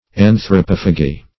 anthropophagy - definition of anthropophagy - synonyms, pronunciation, spelling from Free Dictionary
Search Result for " anthropophagy" : Wordnet 3.0 NOUN (1) 1. human cannibalism ; the eating of human flesh ; The Collaborative International Dictionary of English v.0.48: Anthropophagy \An`thro*poph"a*gy\, n. [Gr.